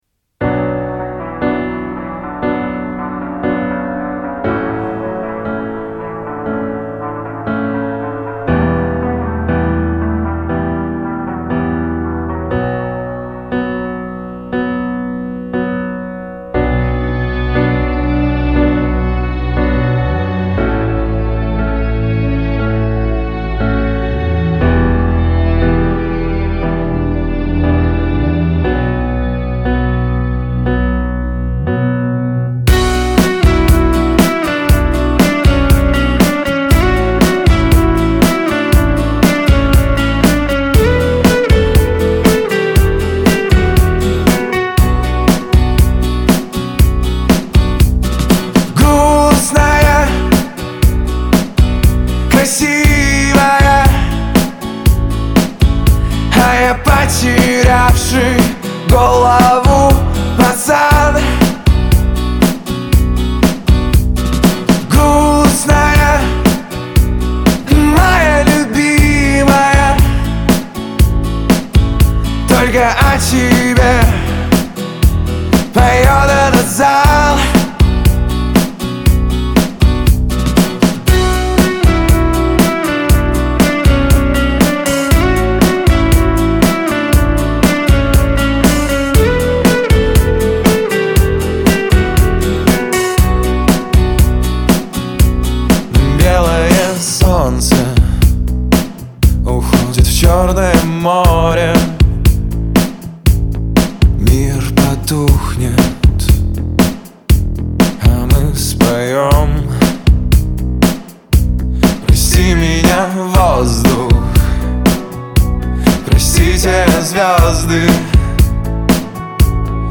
Грустные
Трек размещён в разделе Русские песни / Поп.